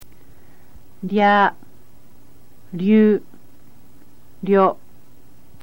In order to make a sound equivalent to KYA rather than have a character for this sound, the character KI is used followed by a small YA.
rya.mp3